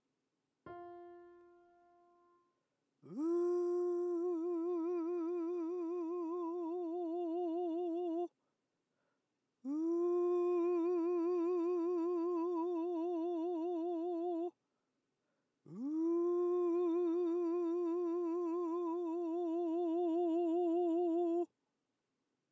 音数はパターン1のまま、一息の中で動き（ビブラートやコブシ）をつけて繋げてみましょう。
音量注意！